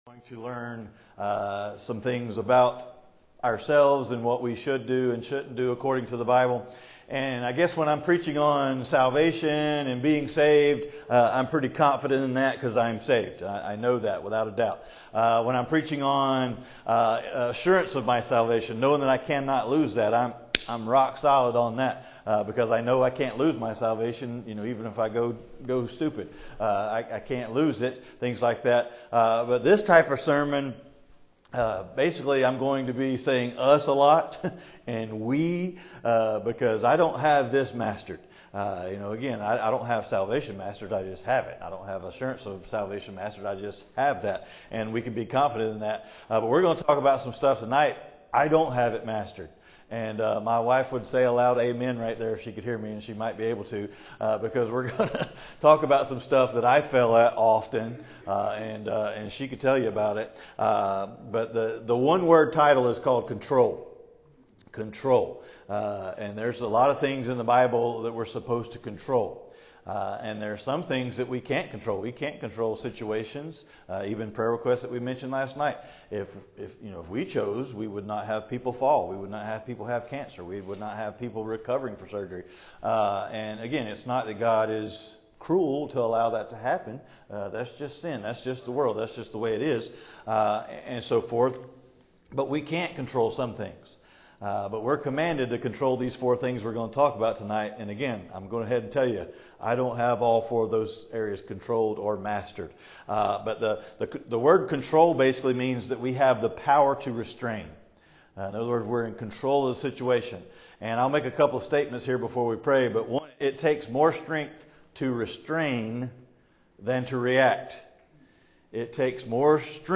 James 3:2 Service Type: Wednesday Night « What to do When You Have The Mulligrubs Know as Much as Possible About Your Opponent